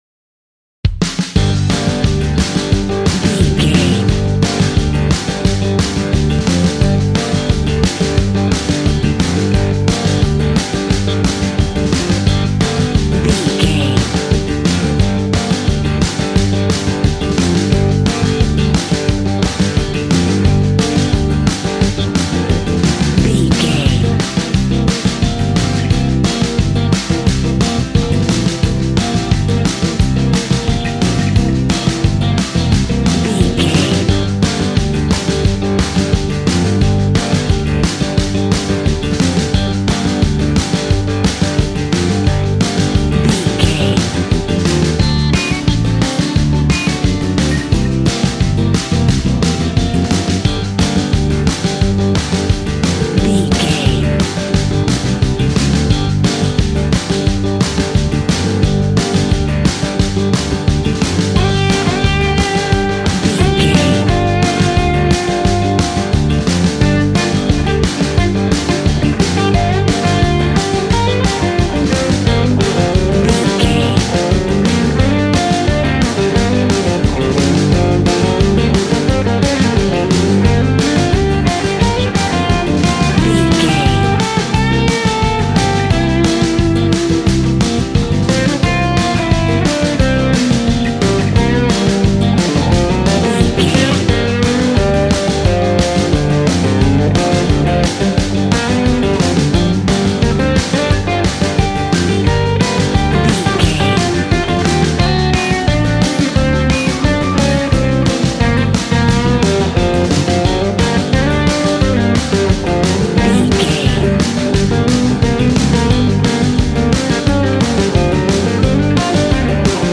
Ionian/Major
cheerful/happy
double bass
drums
piano